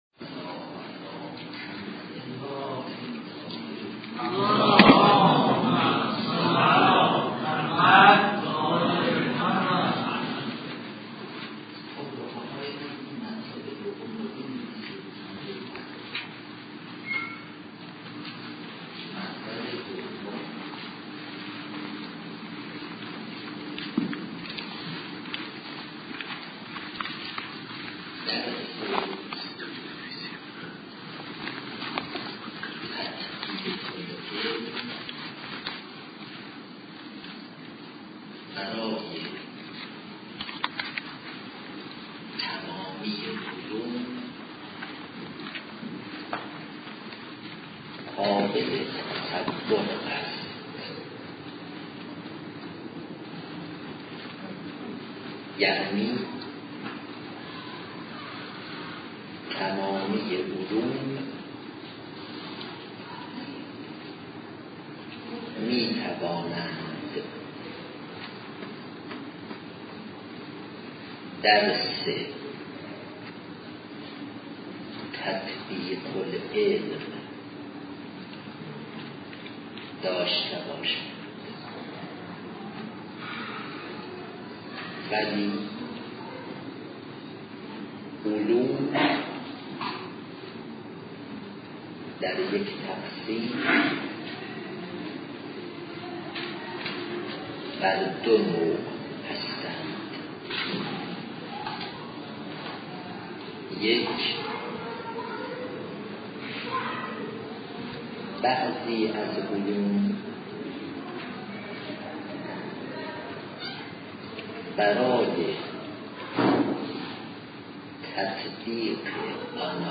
درس تطبیق العلم